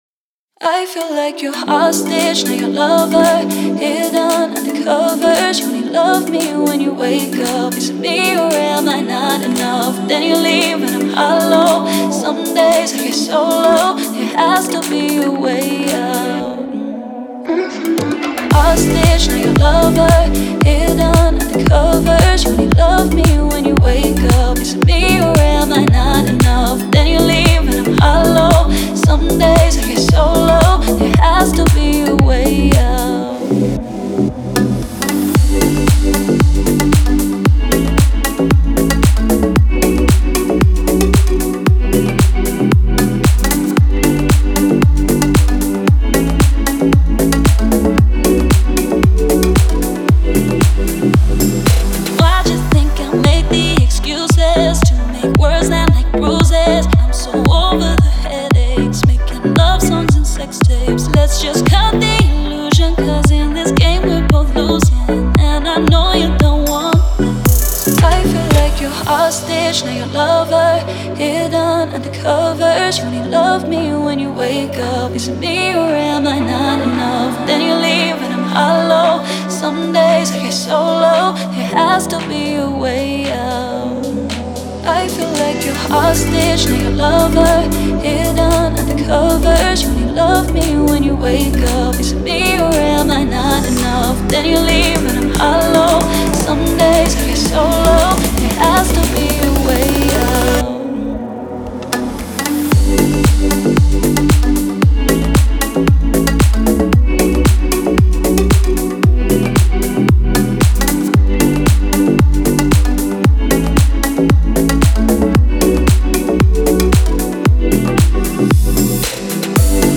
поп-песня